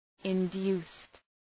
Shkrimi fonetik {ın’du:st}